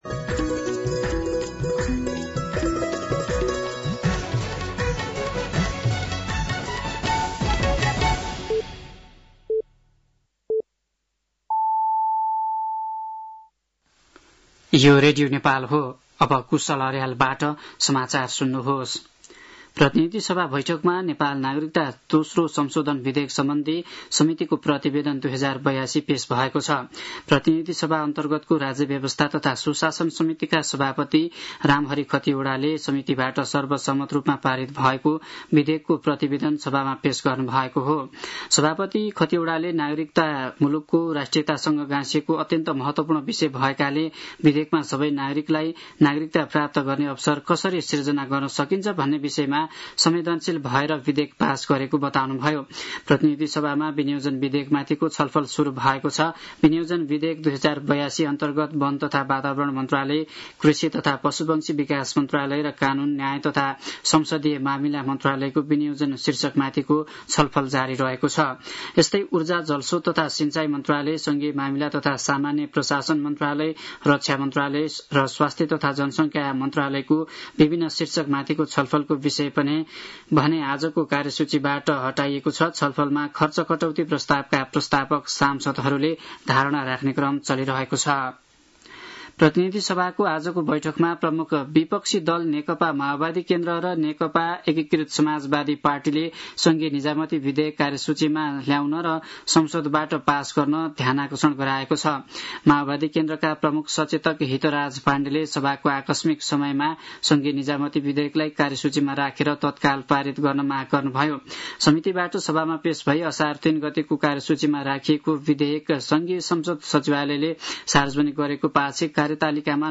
साँझ ५ बजेको नेपाली समाचार : ५ असार , २०८२
5.-pm-nepali-news-2.mp3